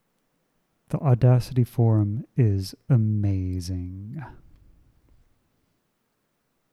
The hiss seems negligible and when I do the noise reduction I’m very happy with the results.